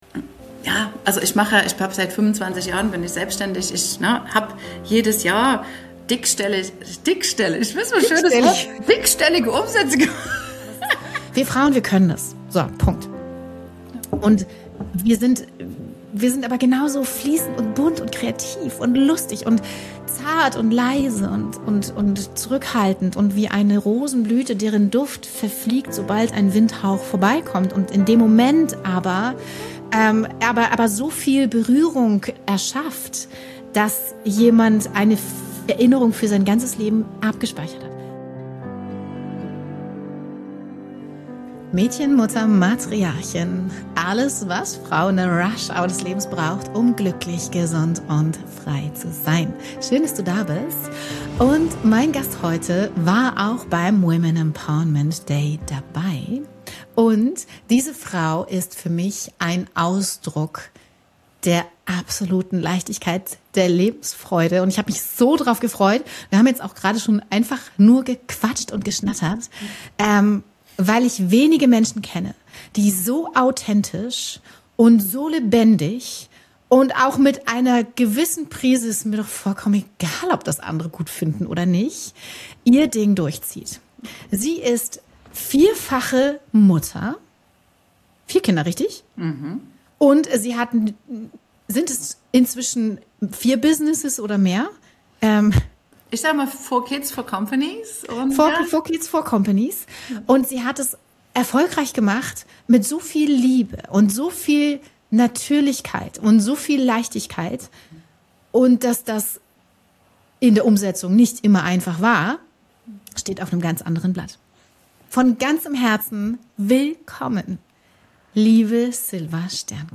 Was wäre, wenn Marketing nicht aus Strategien besteht – sondern aus deiner Energie? In diesem tiefgehenden Gespräch sprechen wir über magnetisches Marketing, authentischen Erfolg und warum dein Business nur so groß werden kann, wie du dich innerlich erlaubst zu wachsen.